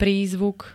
Zvukové nahrávky niektorých slov
rake-prizvuk.ogg